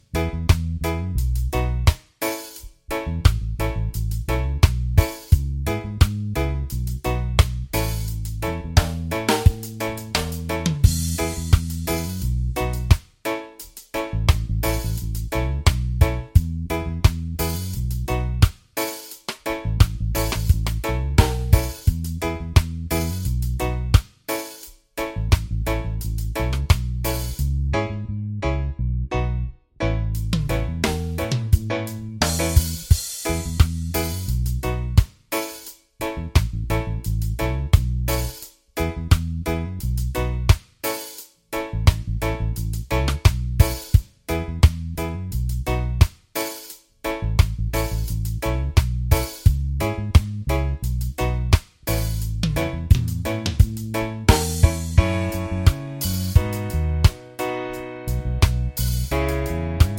Minus Main Guitar For Guitarists 2:56 Buy £1.50